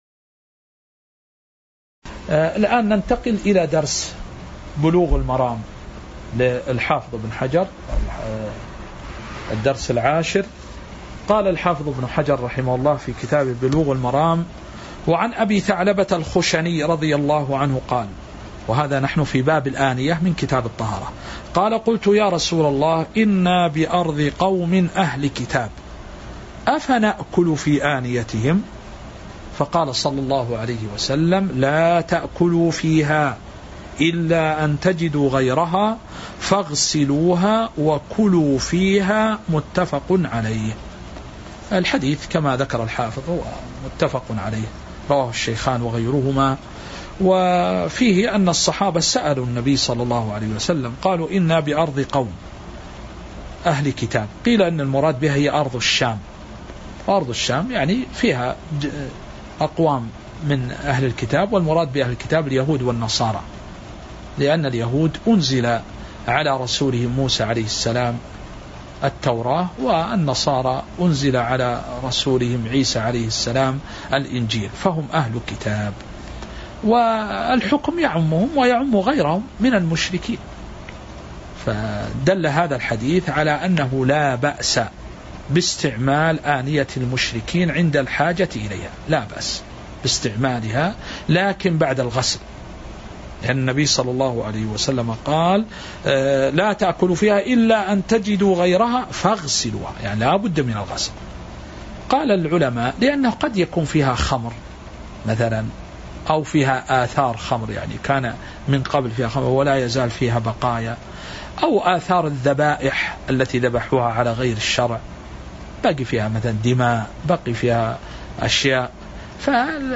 تاريخ النشر ٣٠ شوال ١٤٤٤ هـ المكان: المسجد النبوي الشيخ